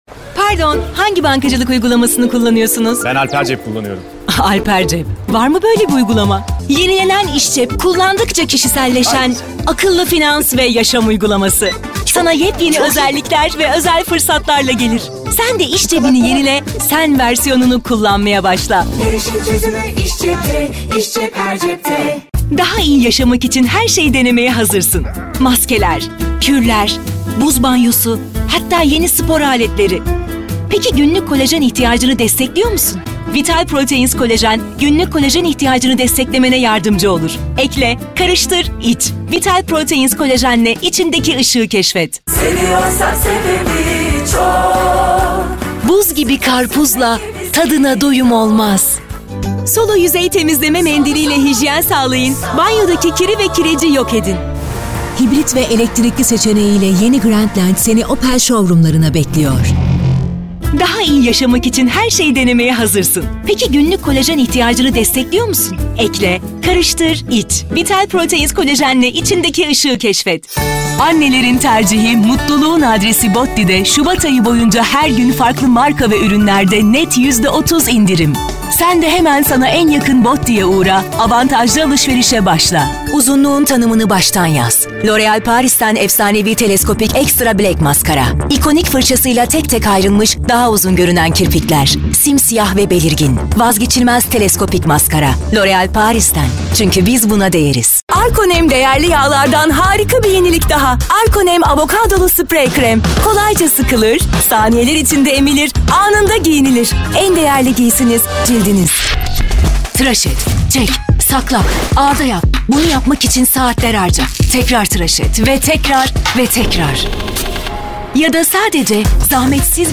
Kadın
KARAKTER Canlı, Eğlenceli, Fragman, Güvenilir, Havalı, Seksi, Animasyon, Karizmatik, Promosyon, Sıcakkanlı, Parlak, Dış Ses,